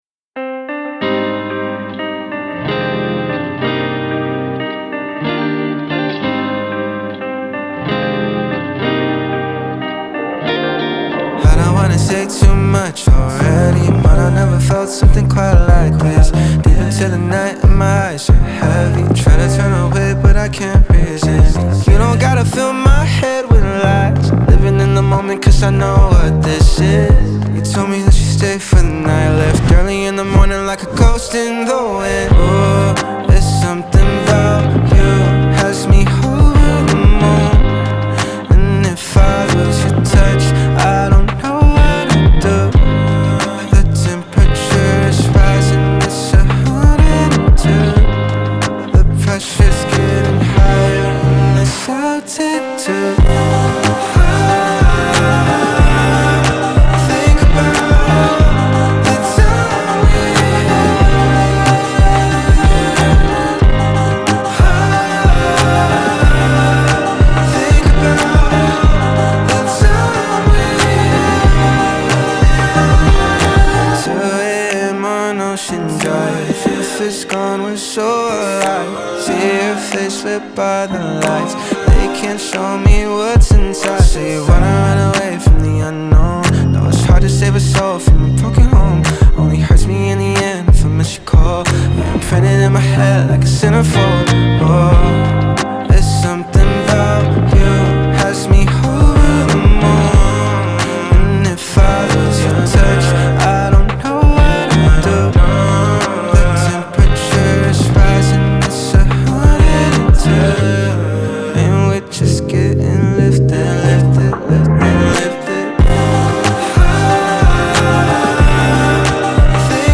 • Жанр: Soul, R&B